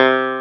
CLAVI6 C3.wav